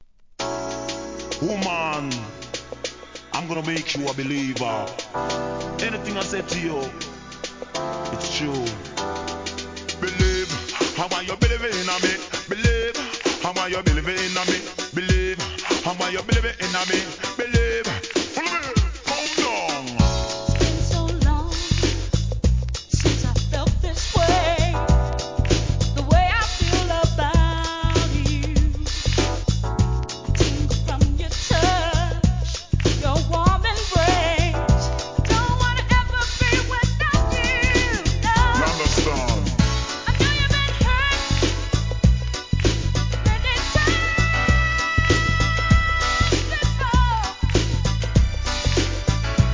HIP HOP/R&B
イントロからラガMCが絡み、中盤にはDANCEHALLリズムにスイッチする1993年作品!!